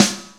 EMX SNR 7.wav